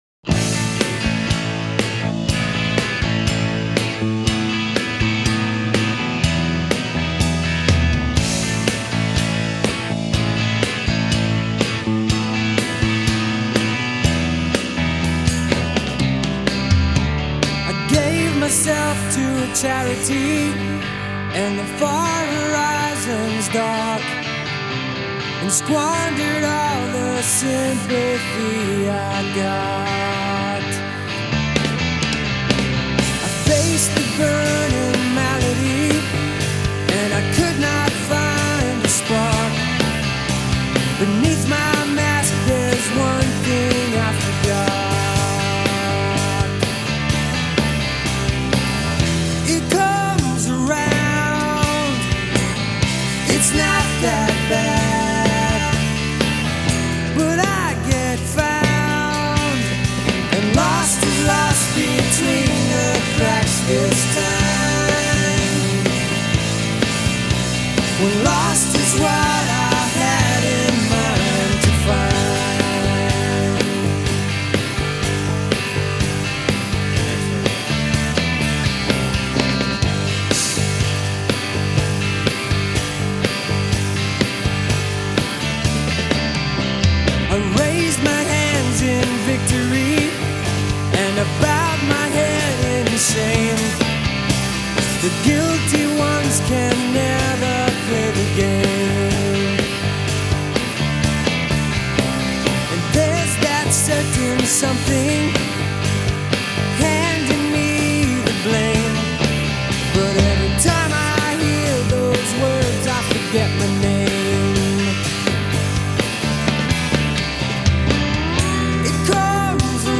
with its addictive guitar lines